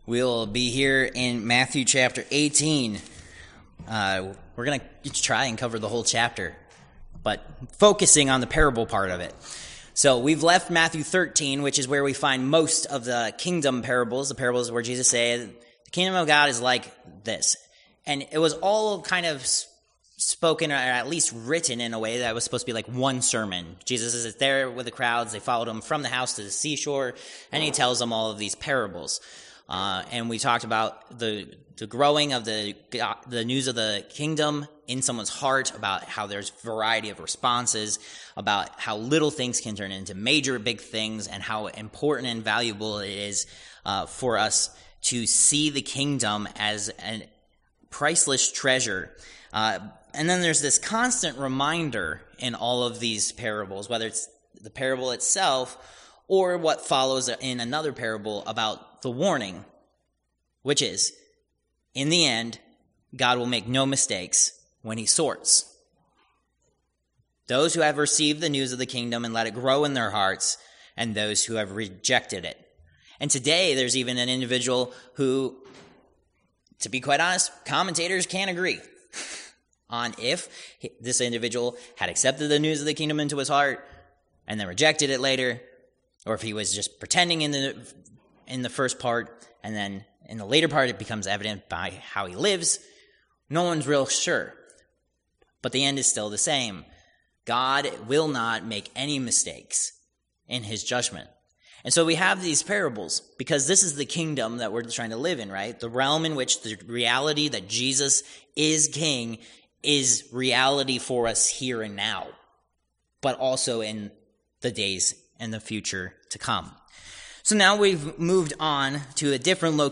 Service Type: Worship Service